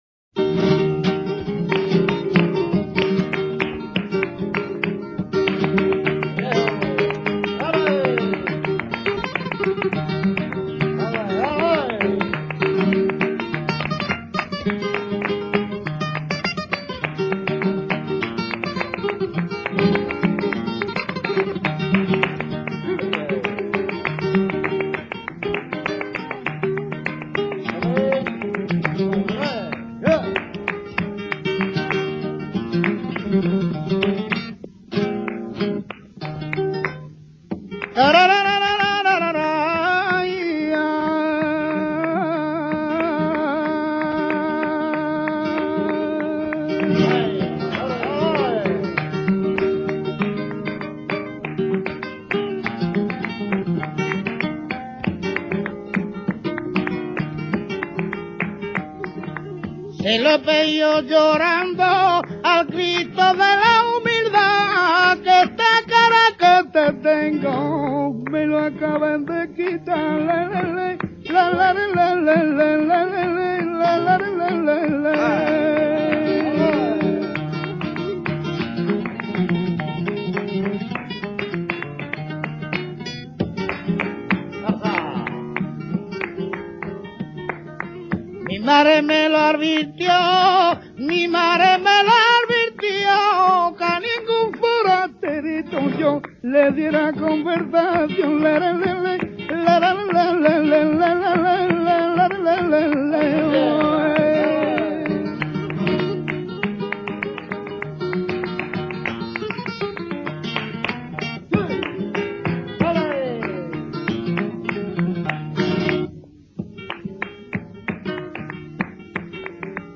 Es uno de los estilos b�sicos del flamenco.
Su ritmo es marcado y muy pegadizo, admitiendo las posturas y las improvisaciones personales.
tango.mp3